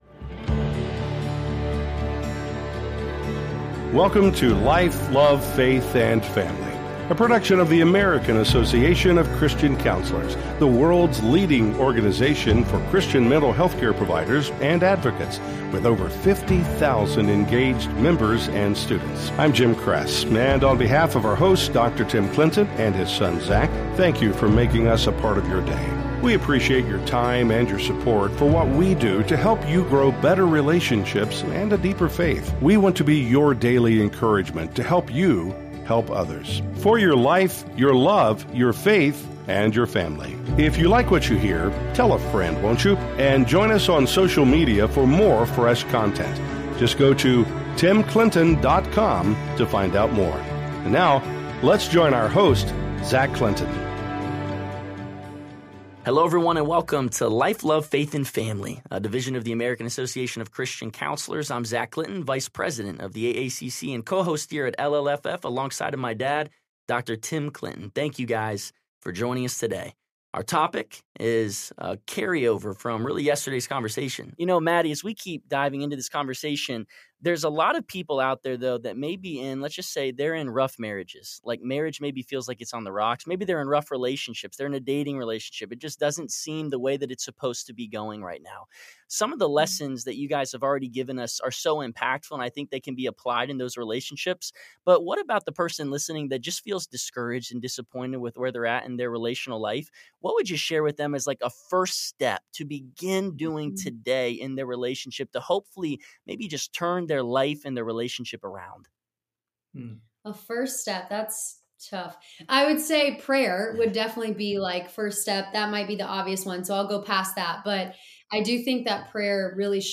heartfelt conversation